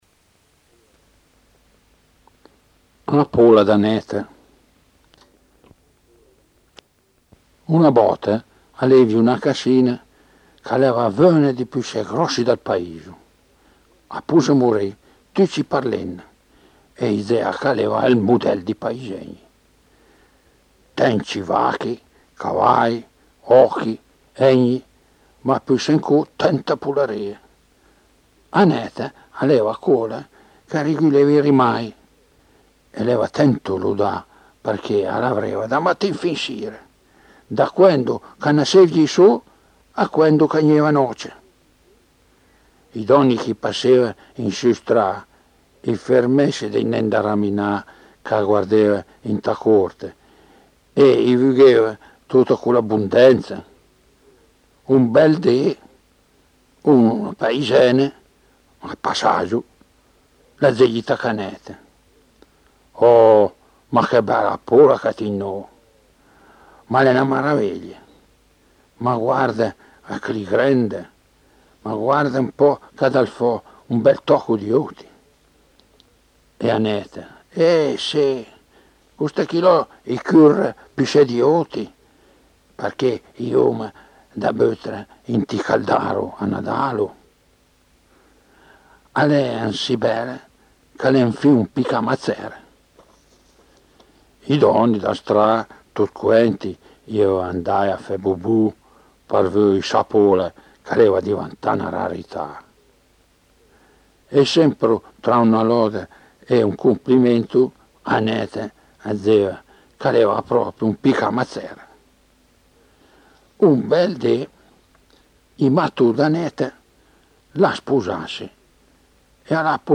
storia satirica, recitata